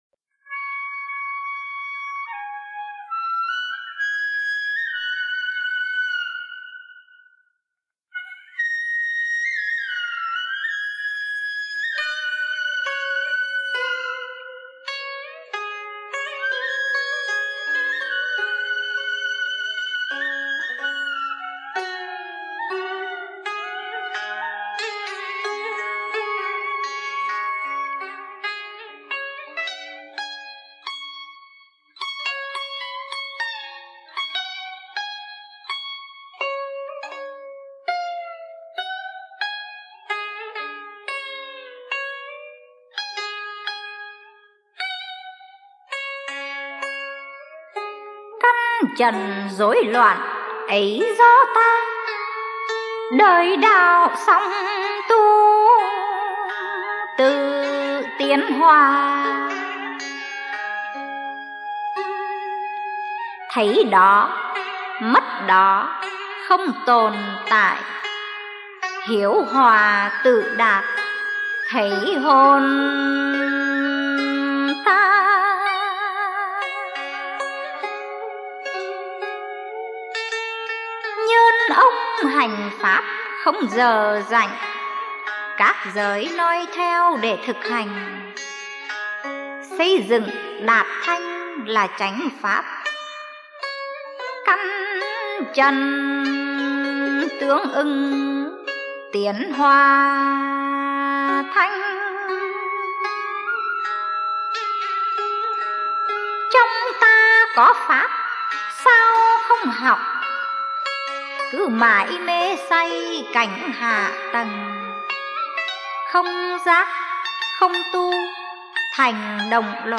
Dân Ca & Cải Lương